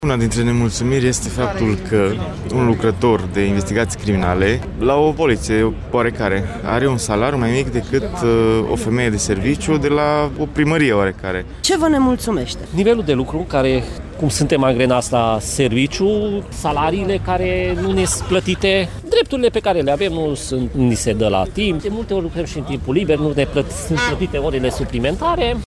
Aproximativ 100 de poliţişti au protestat în faţa Prefecturii Mureş